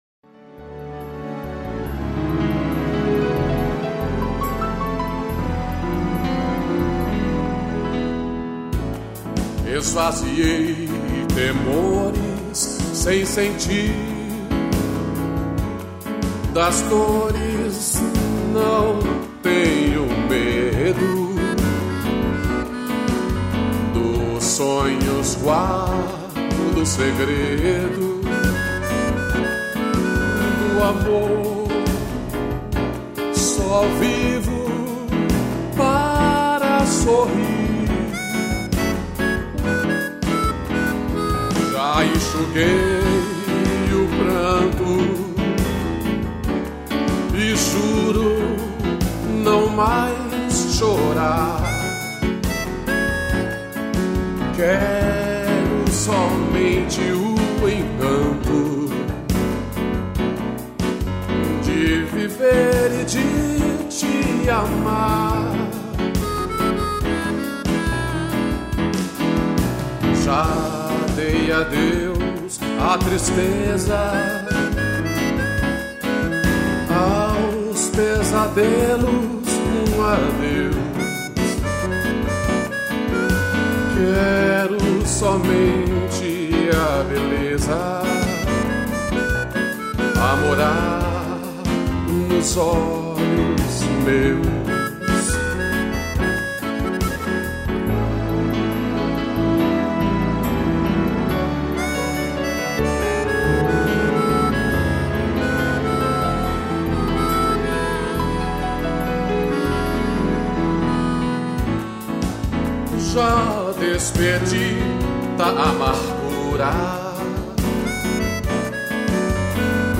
piano e gaita